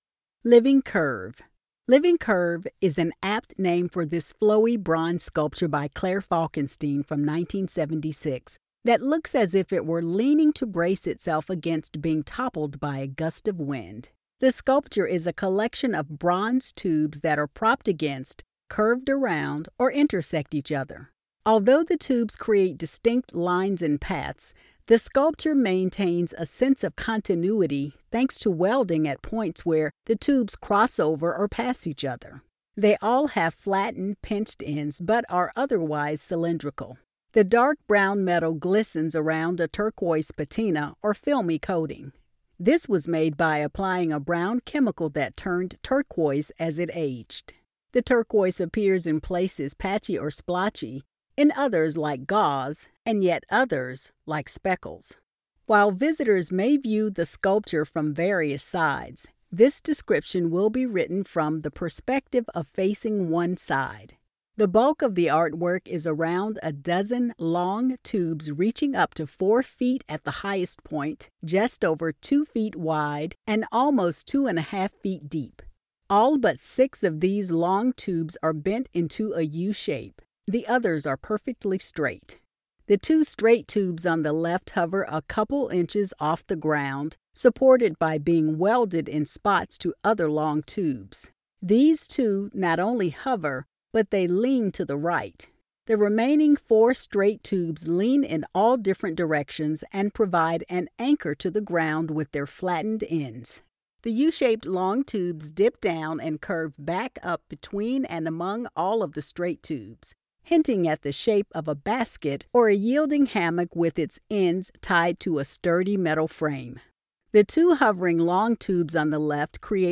Audio Description (02:30)